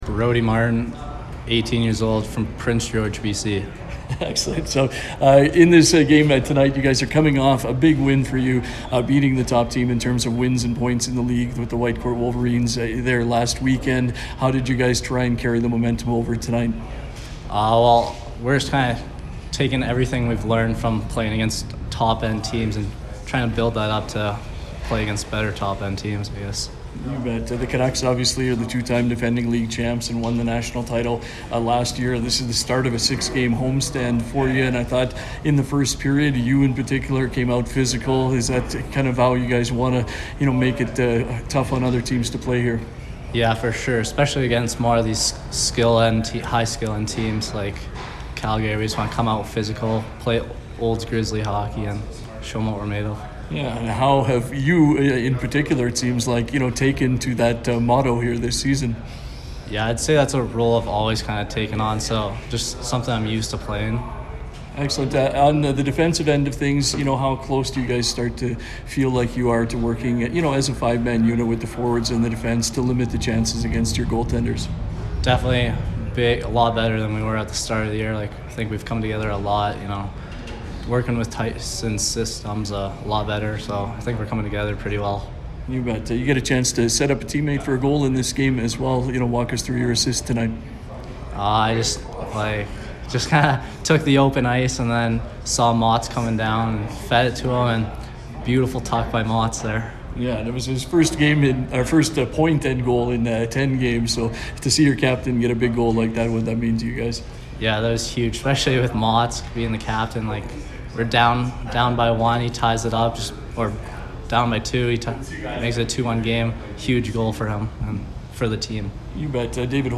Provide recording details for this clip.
post-game audio